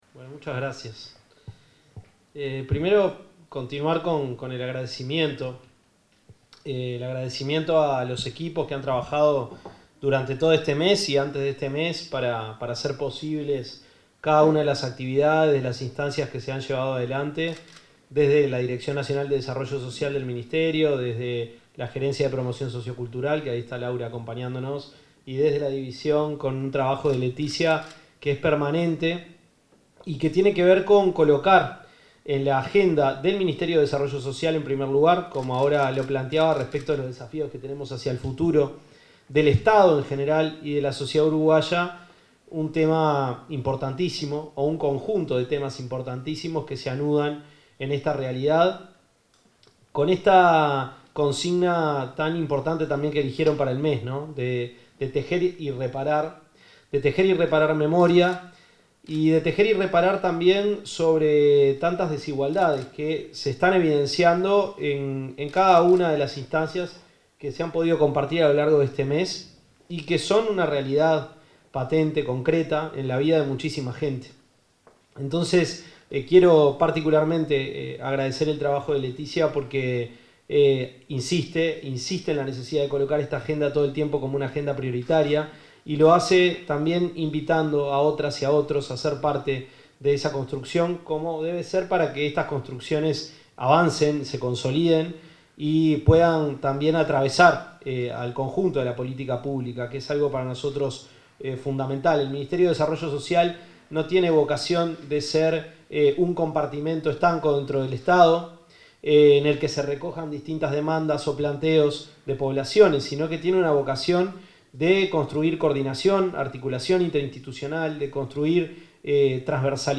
El ministro de Desarrollo Social, Gonzalo Civila, expuso en la actividad de cierre del Mes de la Afrodescendencia, en la que se realizó la